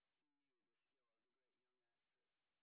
sp02_white_snr20.wav